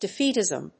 音節de・féat・ism 発音記号・読み方
/‐tɪzm(米国英語), dɪˈfi:tɪzʌm(英国英語)/